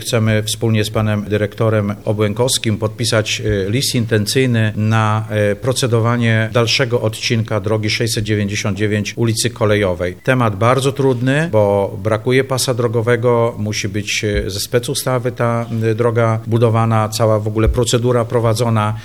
W najbliższej przyszłości remontowi ma być poddany kolejny odcinek drogi krajowej 699, zapowiada Piotr Leśnowolski, burmistrz Jedlni – Letniska